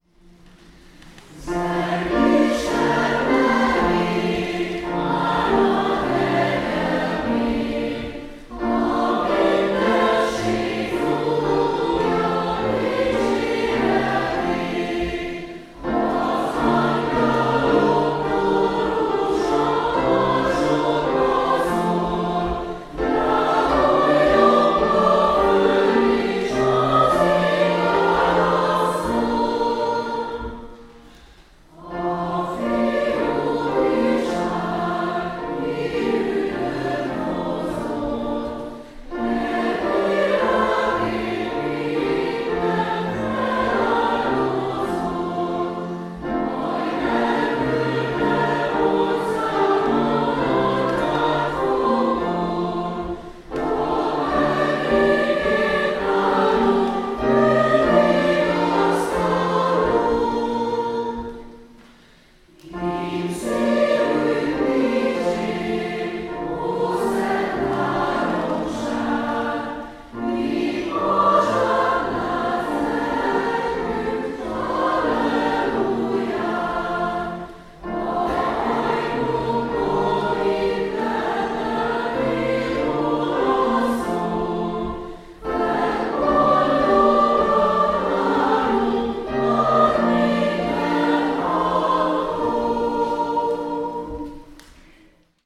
zongorakísérettel magyarul , zenekari kísérettel angolul